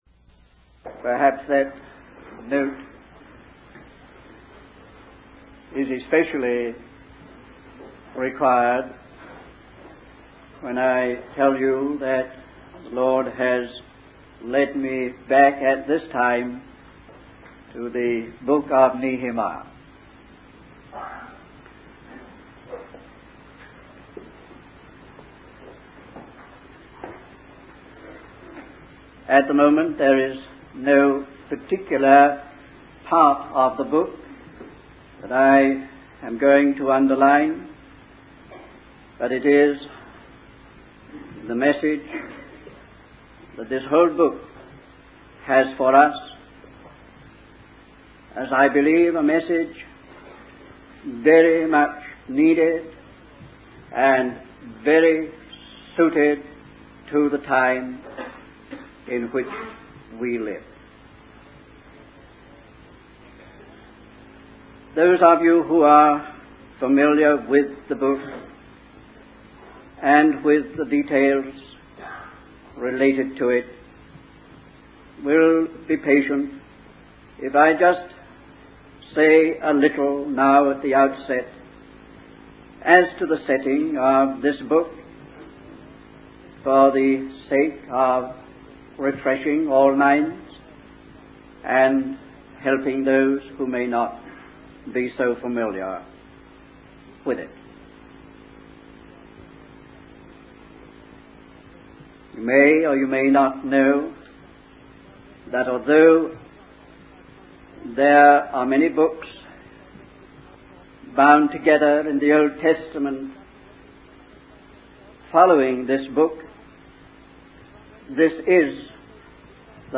In this sermon, the speaker discusses the book of Nehemiah and its significance in the history of God's people. The book is divided into three main sections, each representing a different aspect of the people's journey.